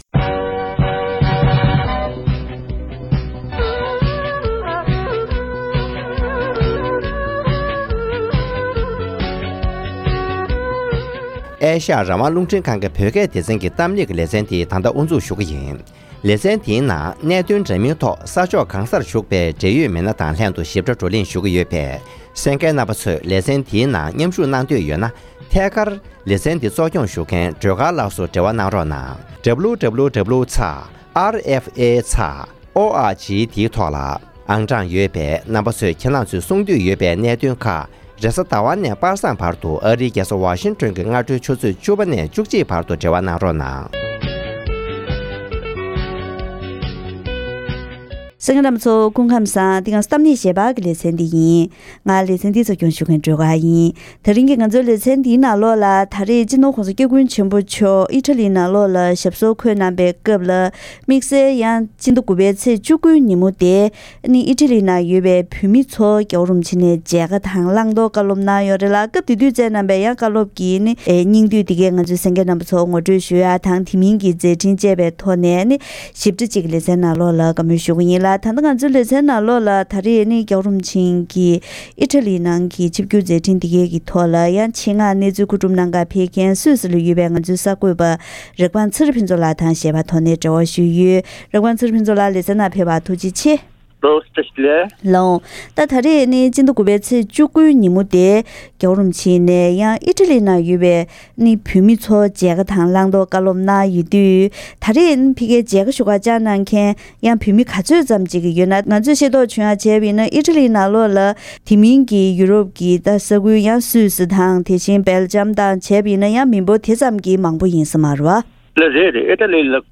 ཨི་ཊ་ལིར་ཡོད་པའི་བོད་མིར་མཇལ་ཁ་དང་བཀའ་སློབ་གནང་བ།